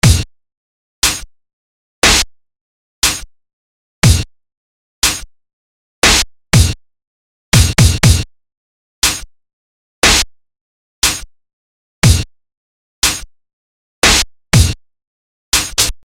硬式鼓点半速
描述：以前的几个循环在60bpm的时候
Tag: 60 bpm Electronic Loops Drum Loops 2.69 MB wav Key : Unknown